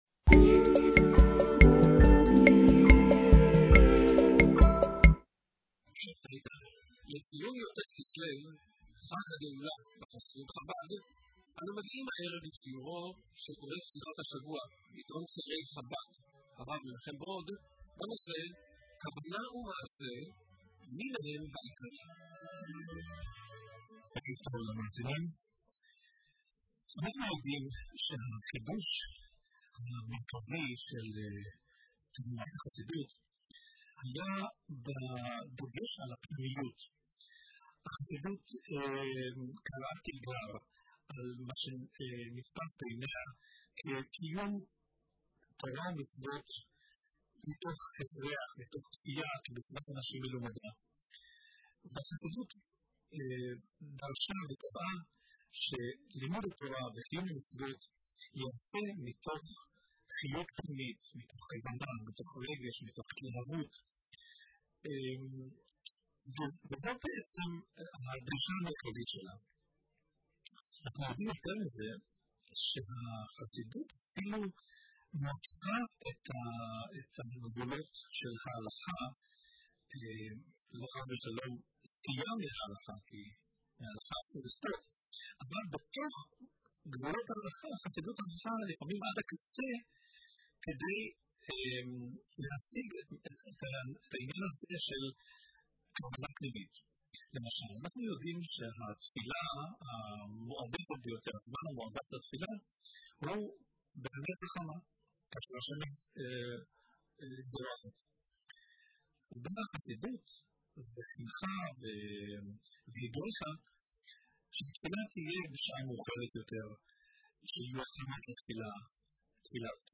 לקראת חג הגאולה: שיעור חסידות ברשת "מורשת"